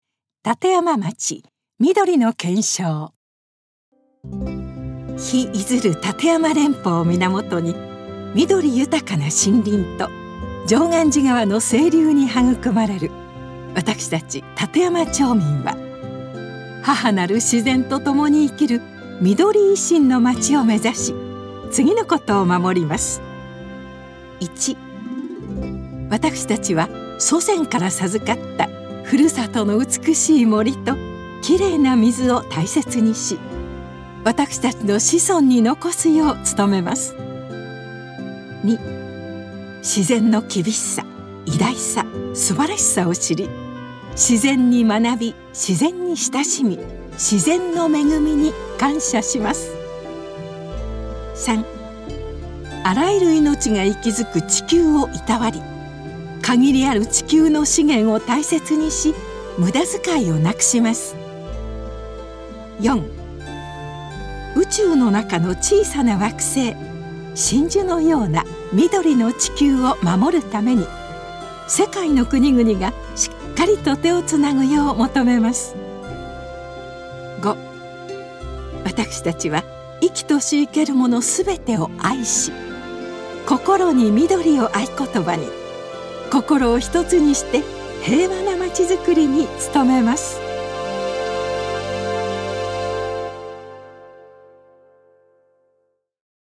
立山町みどりの憲章の朗読 (音声ファイル: 1.9MB)
roudoku_midorinokensyou.mp3